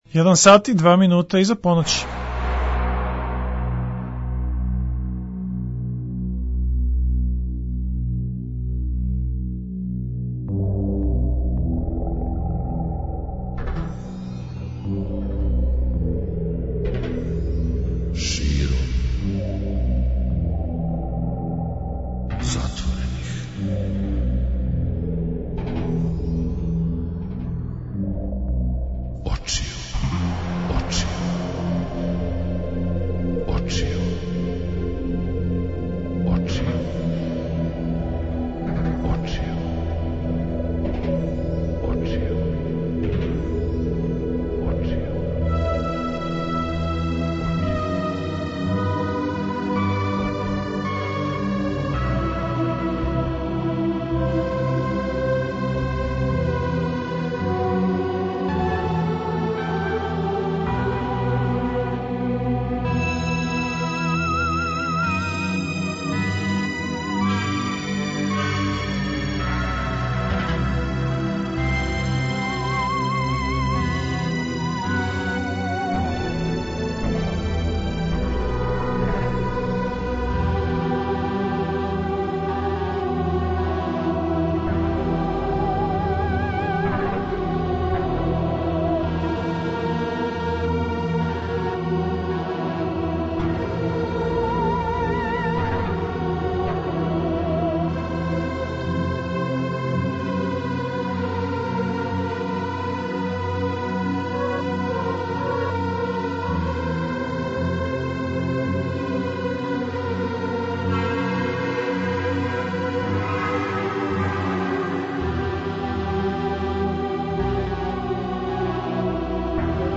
преузми : 42.32 MB Широм затворених очију Autor: Београд 202 Ноћни програм Београда 202 [ детаљније ] Све епизоде серијала Београд 202 Састанак наше радијске заједнице We care about disco!!!